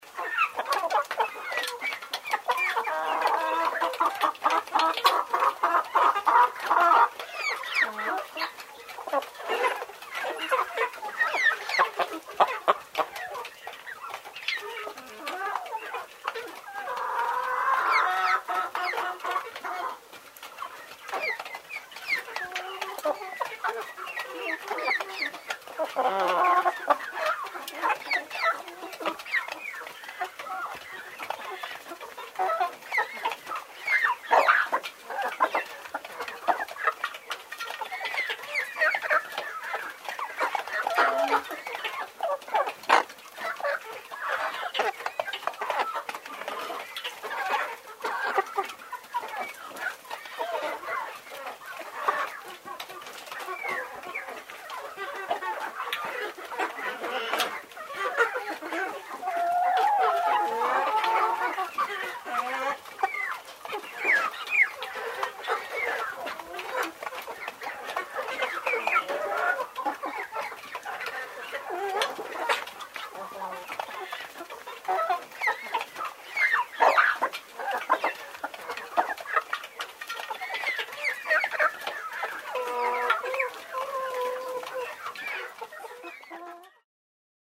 На этой странице собраны разнообразные звуки цыплят: от милого писка до забавного квохтания.
В курятнике среди кур